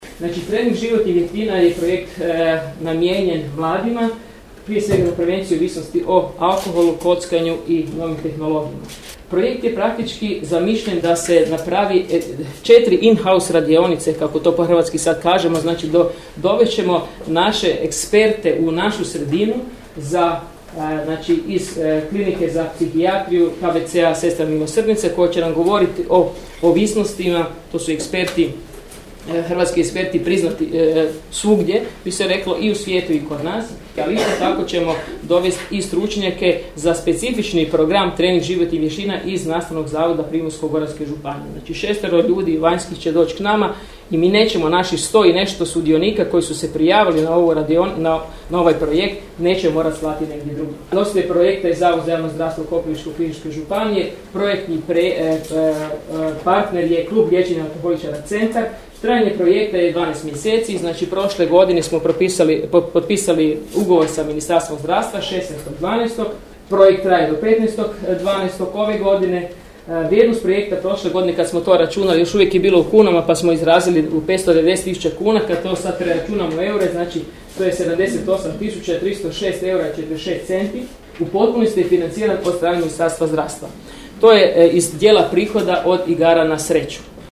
U prostoru Zavoda za javno zdravstvo Koprivničko-križevačke županije održana je Početna konferencija projekta na temu Trening životnih vještina za prevenciju ovisnosti o alkoholu, kockanju i novim tehnologijama kod djece i mladih.